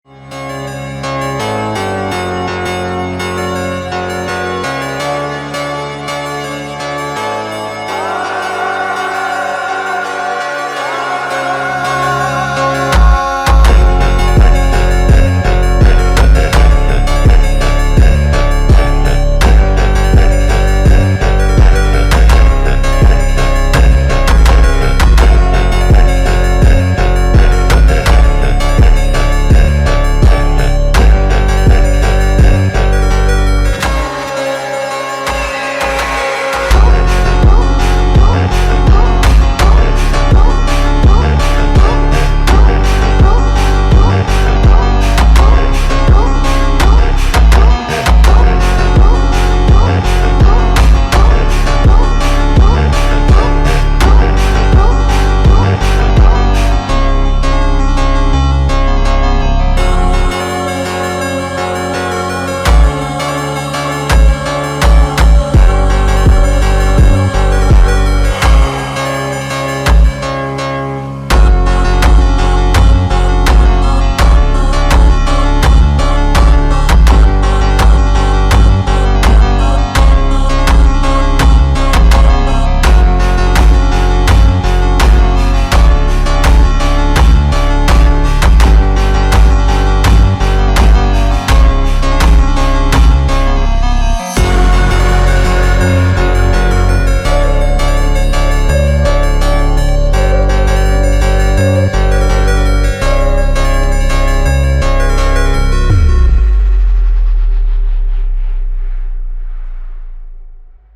𝙨𝙡𝙤𝙬𝙚𝙙 𝙩𝙤 𝙥𝙚𝙧𝙛𝙚𝙘𝙩𝙞𝙤𝙣